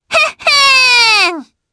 Viska-Vox_Happy4_jp.wav